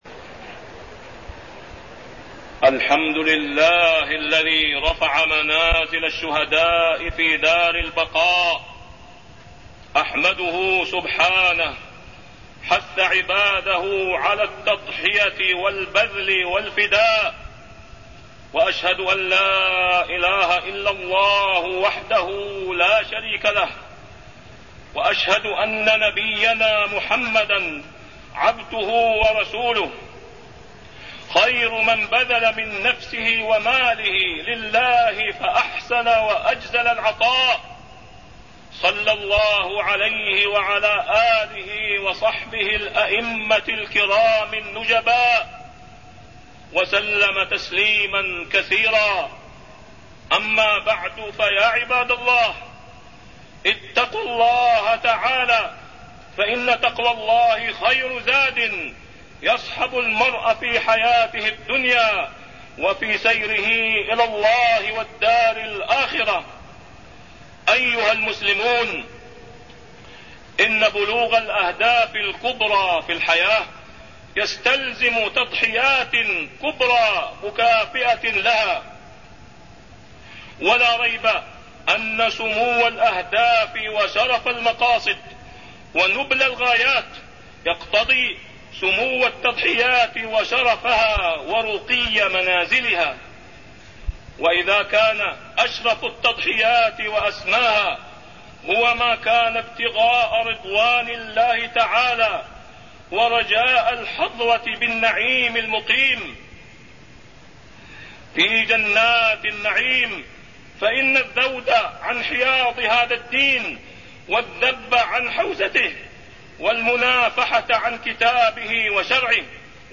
تاريخ النشر ٢٣ رجب ١٤٢١ هـ المكان: المسجد الحرام الشيخ: فضيلة الشيخ د. أسامة بن عبدالله خياط فضيلة الشيخ د. أسامة بن عبدالله خياط التضحية في سبيل الله